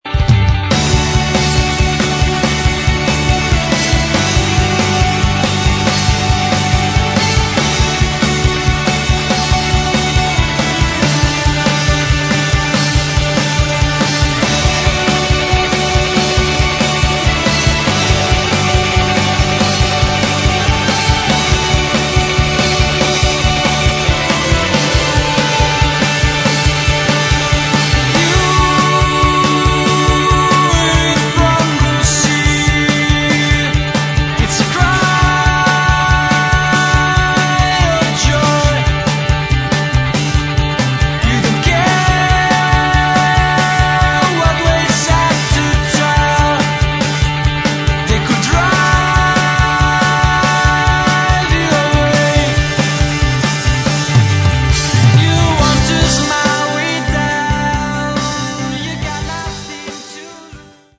Metal
вокал, гитары
бас
ударные